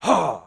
damage_4.wav